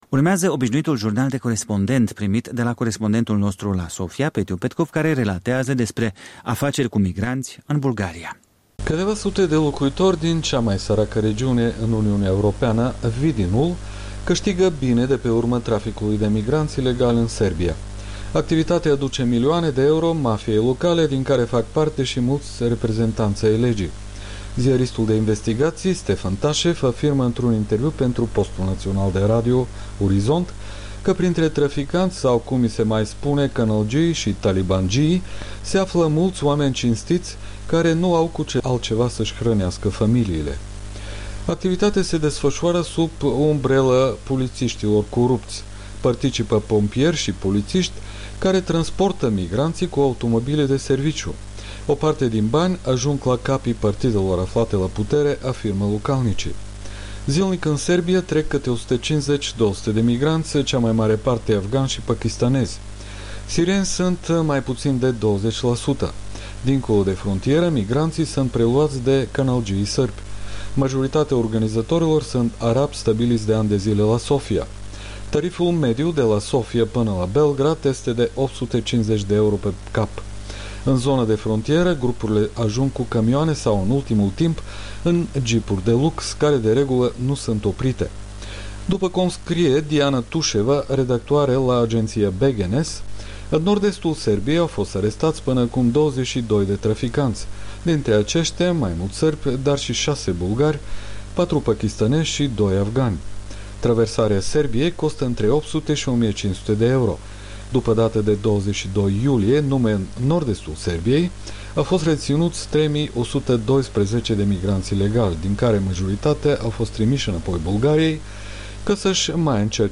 Jurnal de Corespondent